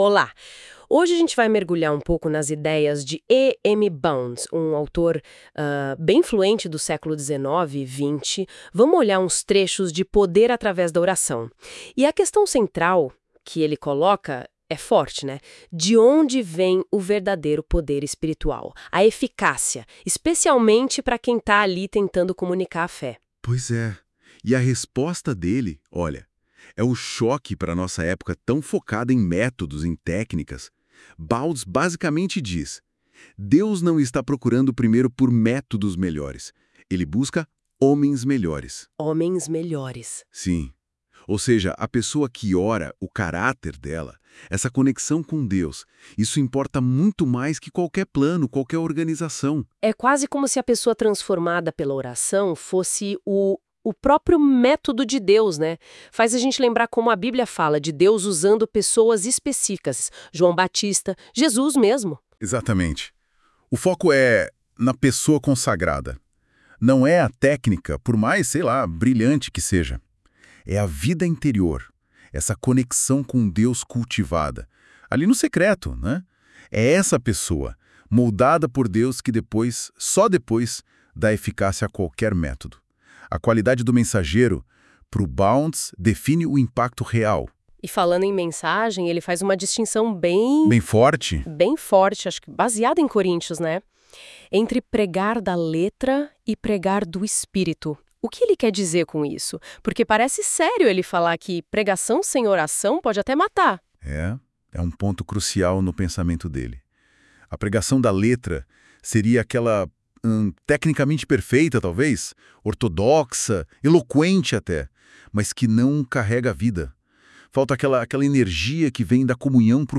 • Confira um podcast bem legal sobre o livro, que geramos usando IA